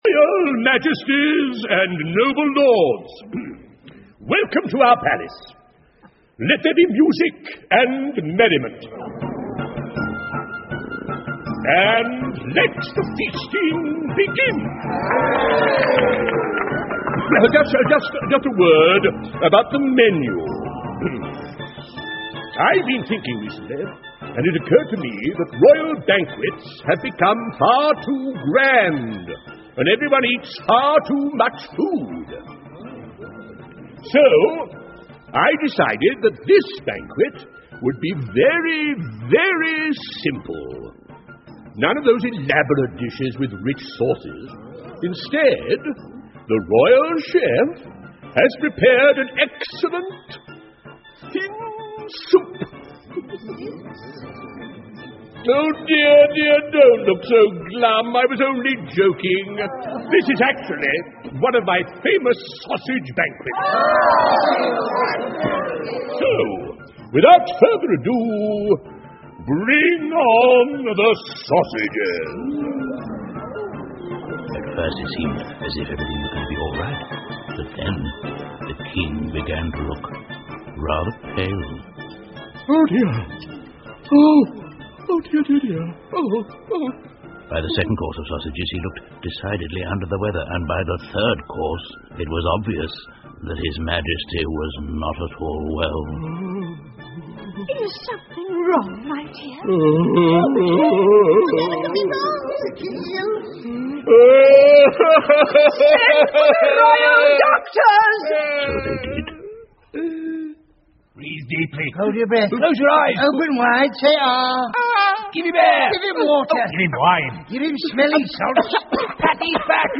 胡桃夹子和老鼠国王 The Nutcracker and the Mouse King 儿童广播剧 16 听力文件下载—在线英语听力室